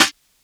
Night Call Snare.wav